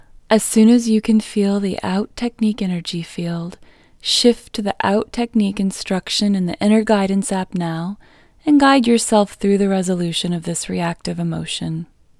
LOCATE OUT English Female 38